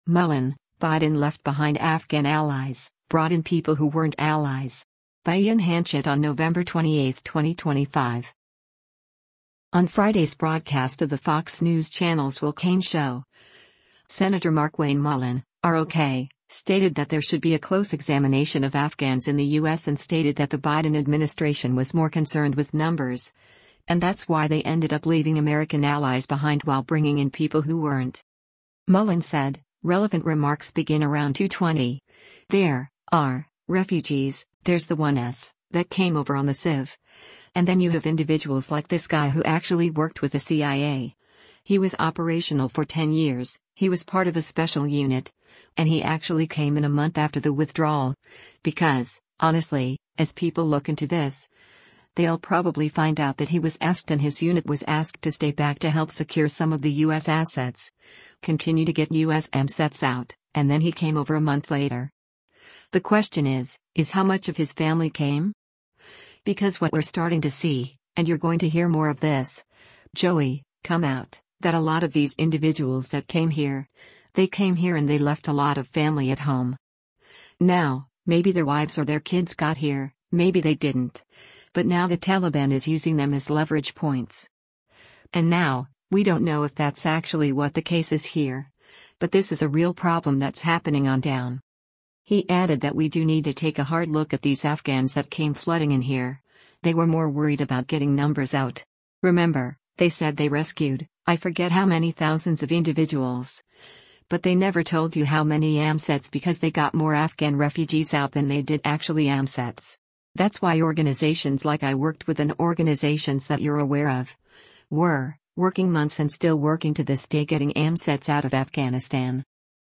On Friday’s broadcast of the Fox News Channel’s “Will Cain Show,” Sen. Markwayne Mullin (R-OK) stated that there should be a close examination of Afghans in the U.S. and stated that the Biden administration was more concerned with numbers, and that’s why they ended up leaving American allies behind while bringing in people who weren’t.